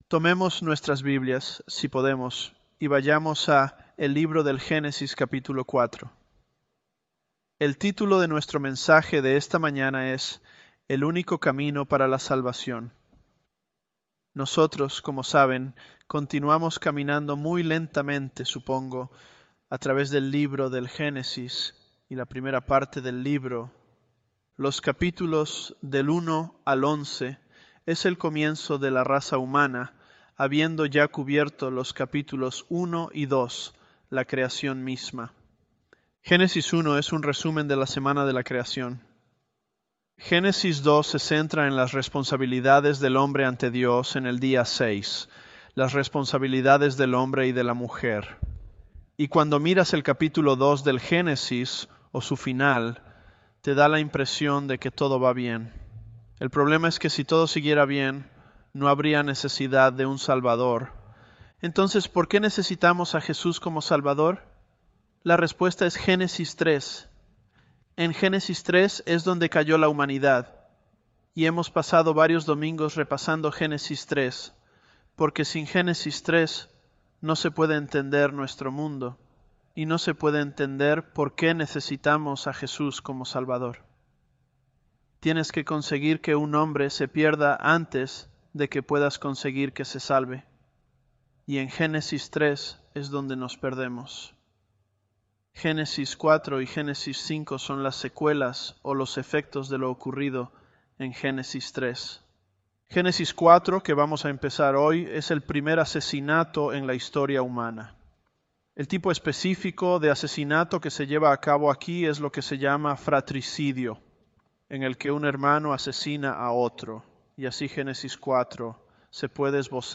ElevenLabs_Genesis-Spanish016.mp3